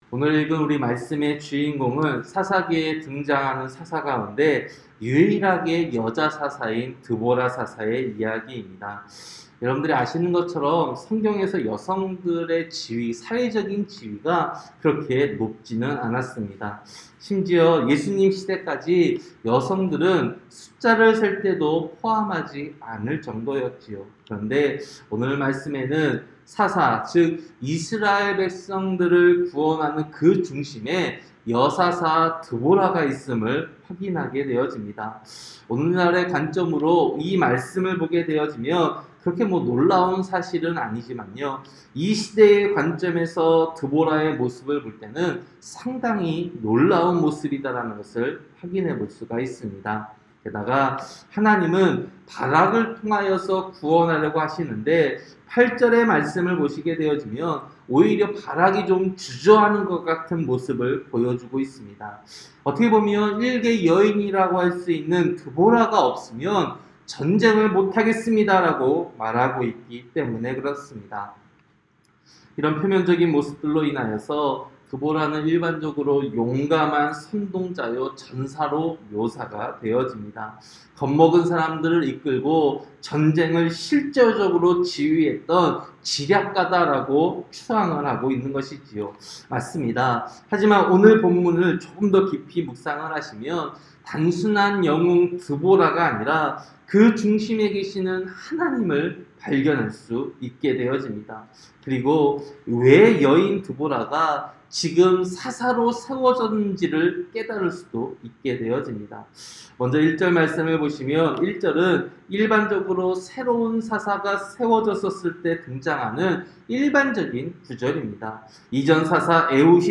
새벽설교-사사기 4장